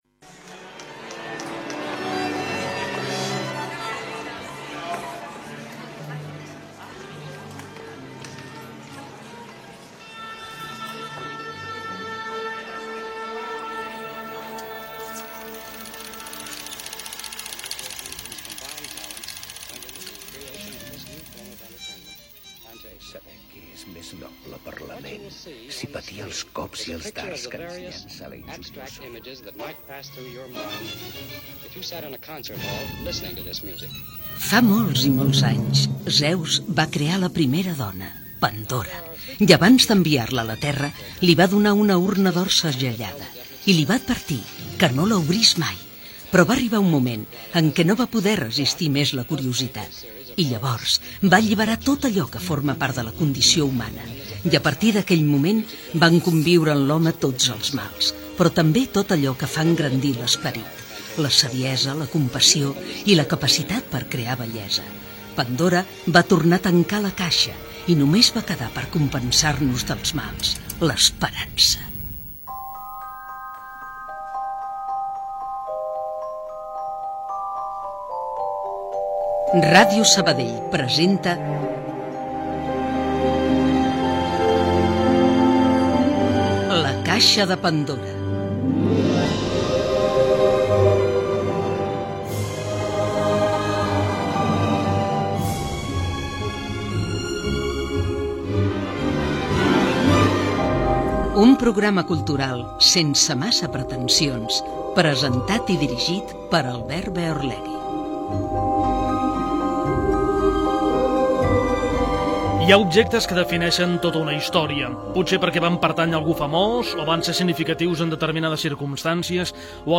Careta del programa, la subhasta d'un violí trobat a l'enfonsament del Titànic, indicatiu, presentació de l'equip i els continguts, cita d'Oscar Wilde.